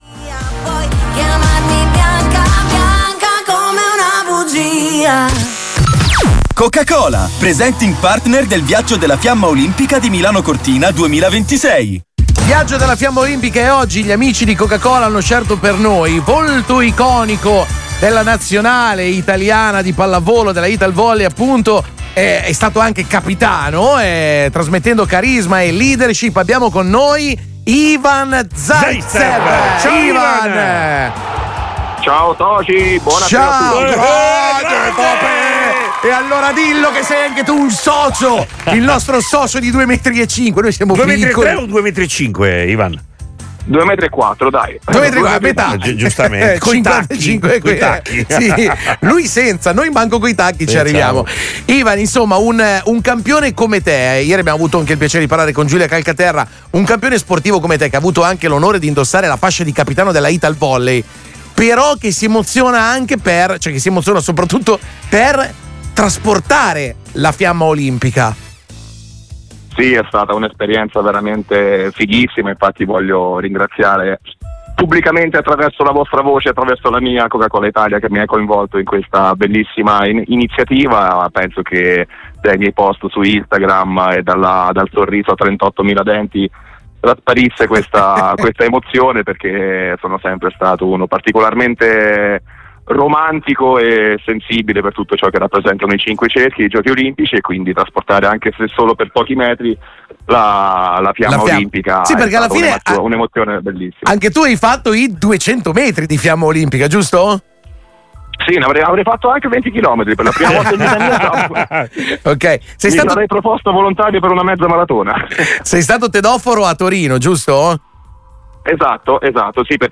Lo abbiamo invitato a raccontare la sua emozione in onda ai microfoni di Radio 105: